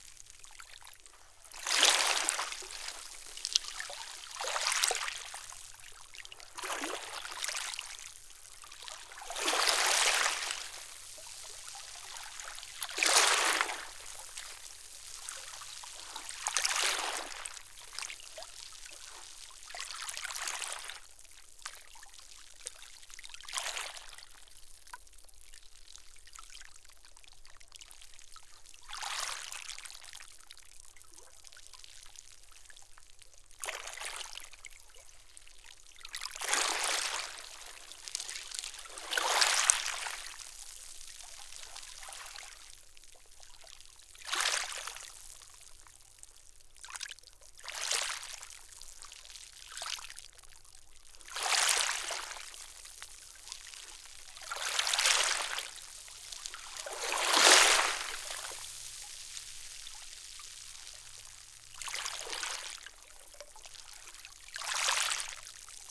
SND_water_player_head_loop.ogg